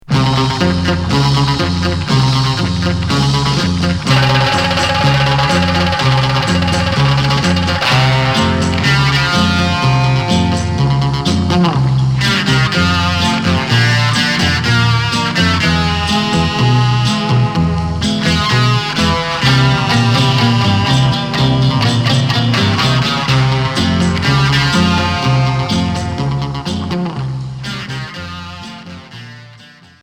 Rock instrumental Neuvième EP retour à l'accueil